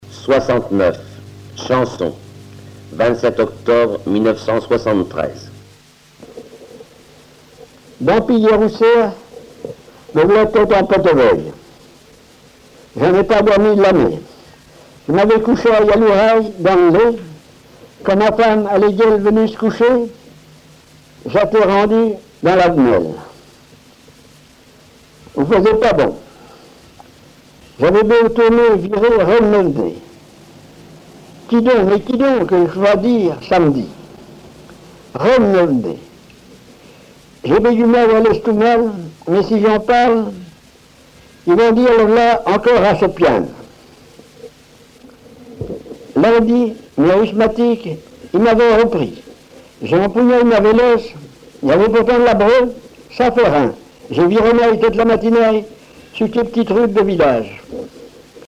Chanson
chanteur(s), chant, chanson, chansonnette
Patois local